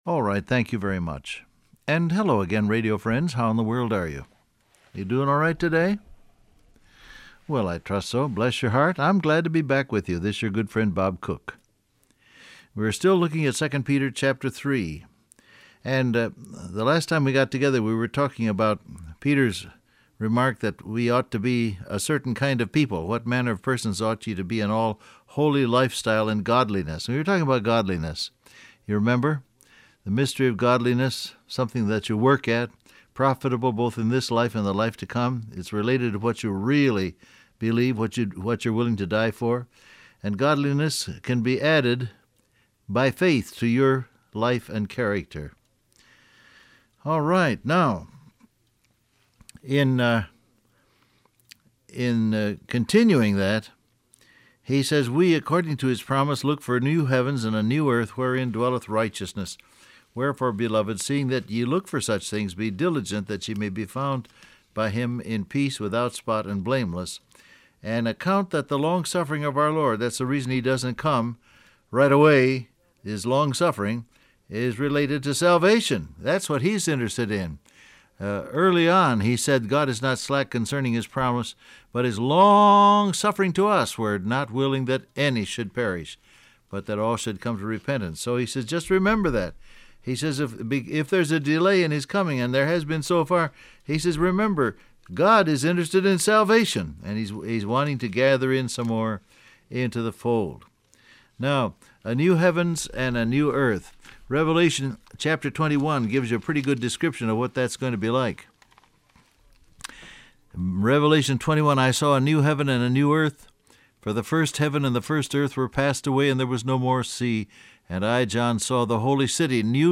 Download Audio Print Broadcast #7265 Scripture: 2 Peter 3:14 , Revelation 21 Topics: Surrender , Peace With God , Compassion , Finding Peace Transcript Facebook Twitter WhatsApp Alright, thank you very much.